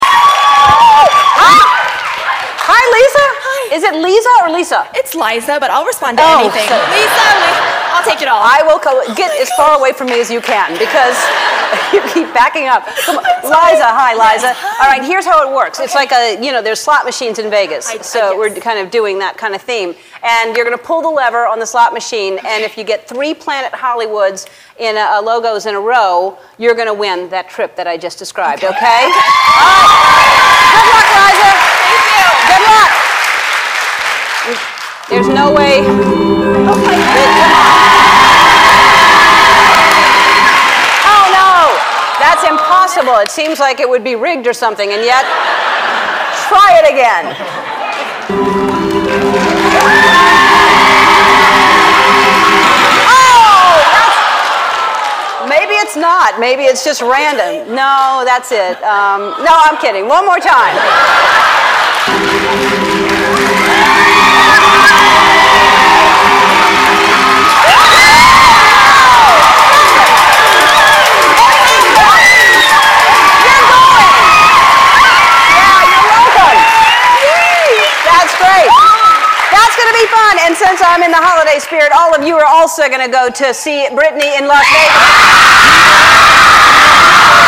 在线英语听力室艾伦脱口秀41 节日发福利的听力文件下载,艾伦脱口秀是美国CBS电视台的一档热门脱口秀，而主持人Ellen DeGeneres以其轻松诙谐的主持风格备受青睐。